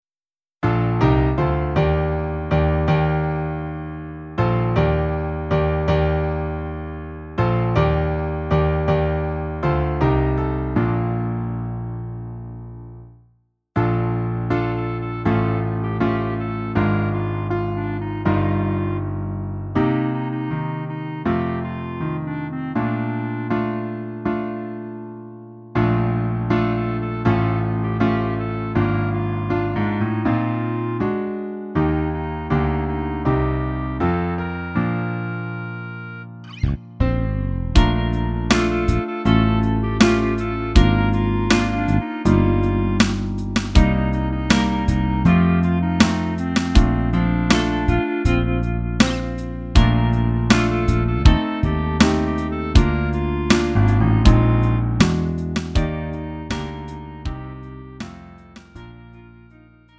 음정 원키 4:07
장르 가요 구분 Lite MR